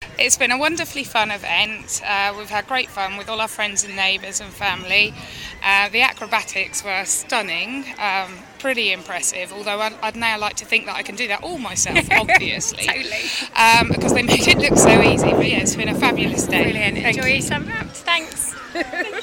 Audiences talk about Eastleigh Unwrapped!